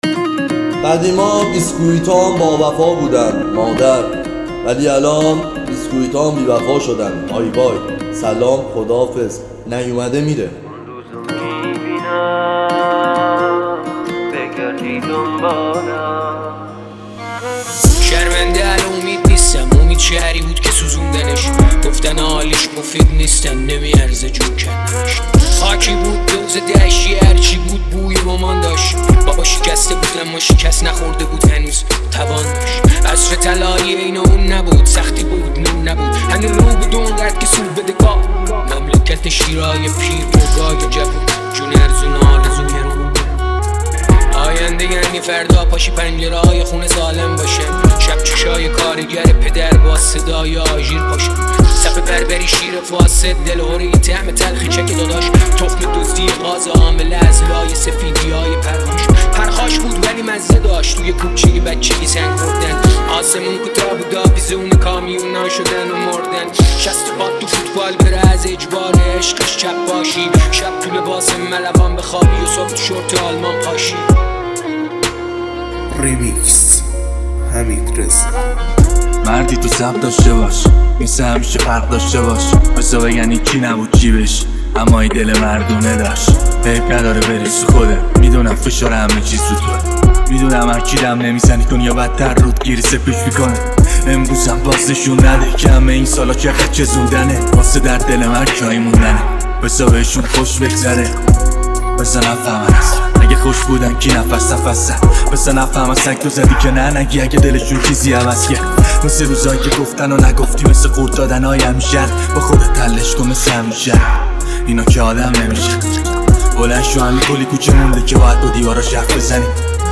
ریمیکس رپ